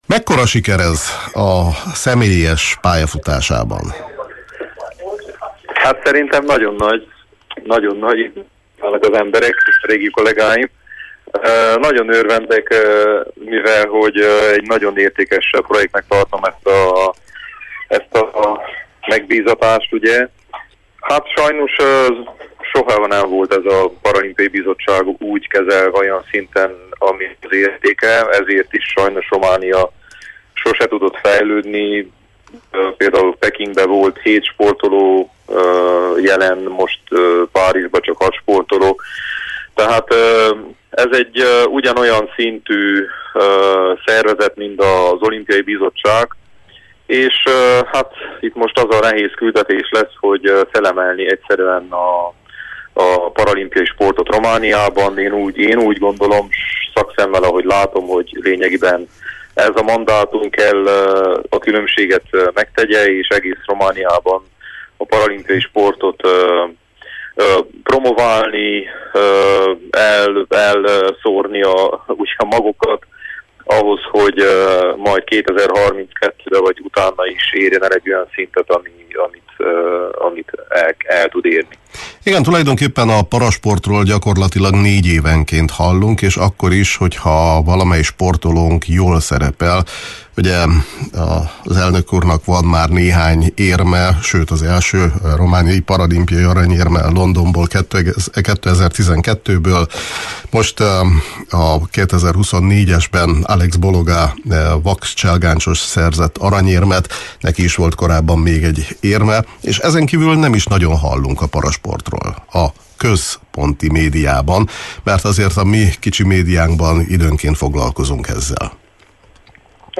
beszélgetett a Kispad című sportműsorunkban